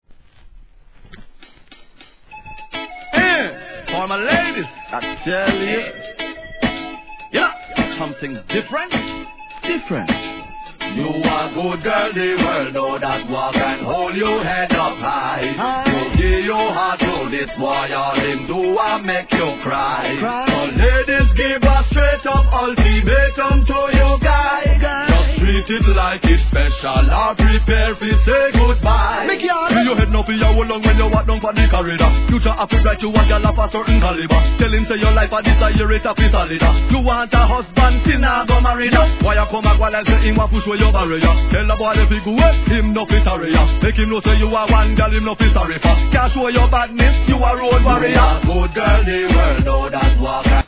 REGGAE
陽気なメロディ〜が爽快な